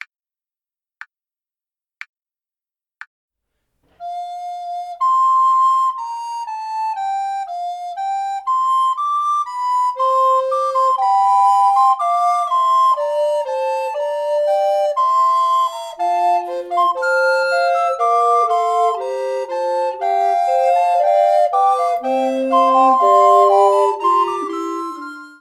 Play-along files